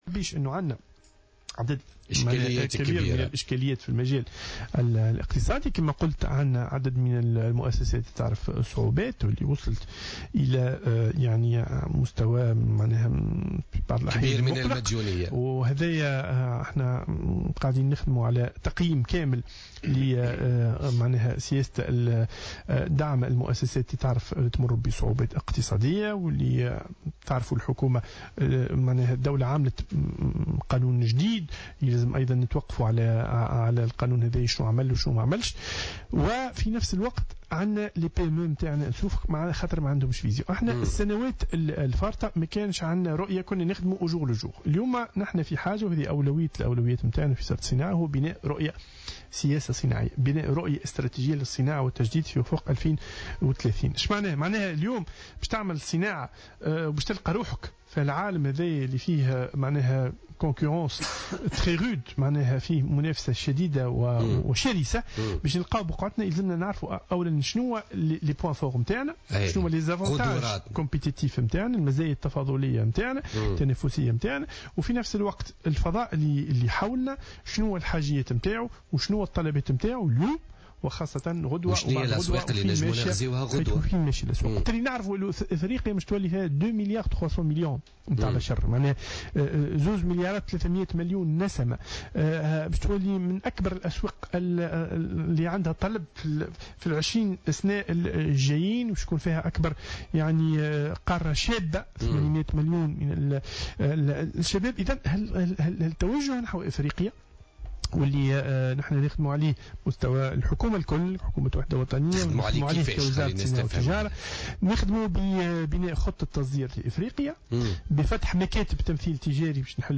وأوضح العذاري، ضيف برنامج "بوليتيكا" اليوم أن هذه المؤسسات وصلت إلى وضعية "مقلقة"، مشيرا إلى أن الوزارة تعمل على تقييم كامل لسياسة دعم المؤسسات التي تمر بصعوبات وبناء رؤية استراتيجية للصناعة والتجديد في أفق سنة 2030 .